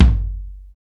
KIK XR.BDR02.wav